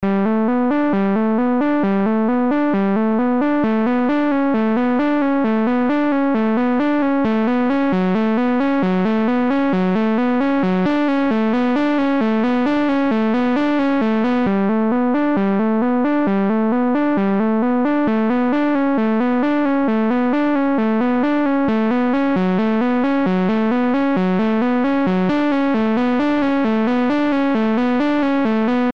Shoegaze electro/alternative
Here's what I remember from the intro synth -- at some point a male vocal comes in singing "how fast we fall" or some such.